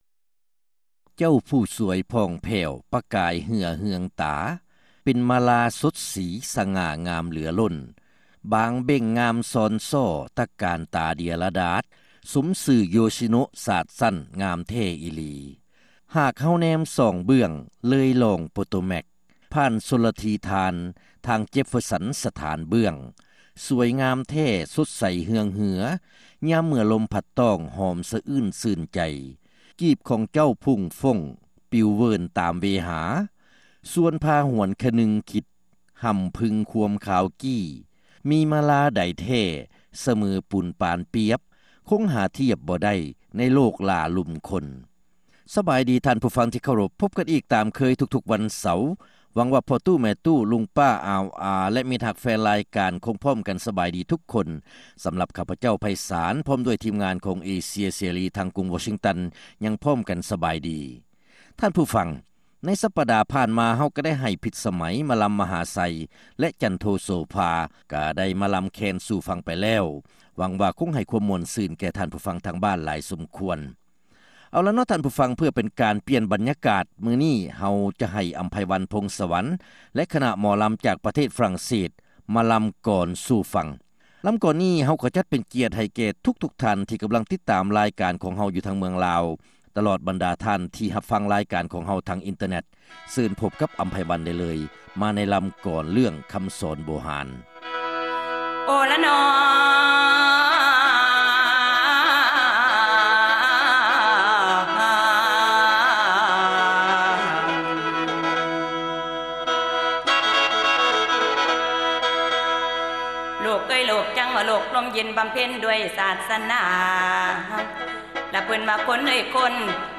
ຣາຍການໜໍລຳ ປະຈຳສັປະດາ ວັນທີ 31 ເດືອນ ມິນາ ປີ 2006